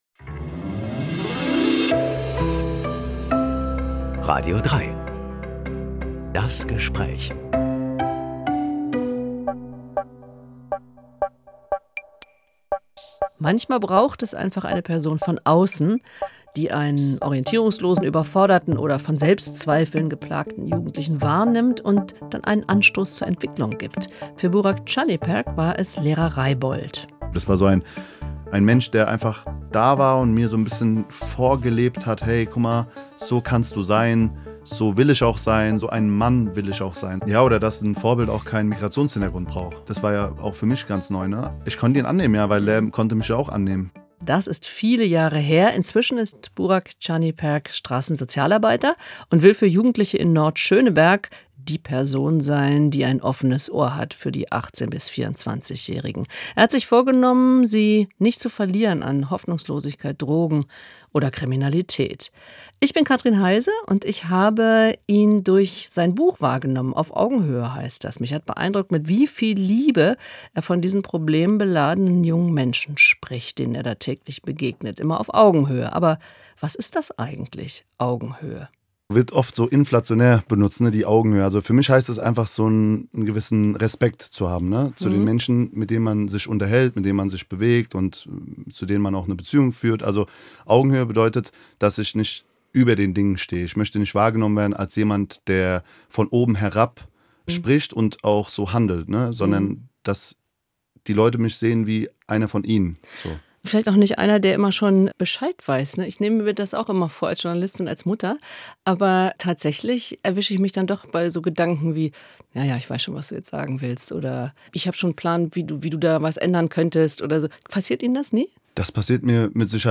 Eine Wiederholung vom 25. Mai 2025.